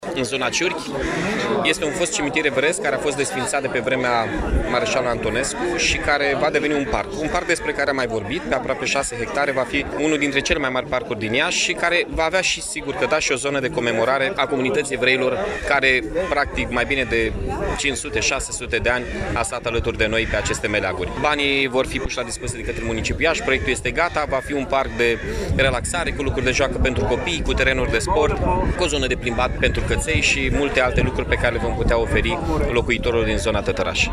La Cimitirul Evreiesc, a avut loc o manifestare în cadrul căreia au fost rememorate evenimentele triste petrecute în urmă cu 83 de ani, dar și un moment simbolic de recunoaştere a meritelor deosebite ale comunităţii evreieşti în dezvoltarea Iaşiului.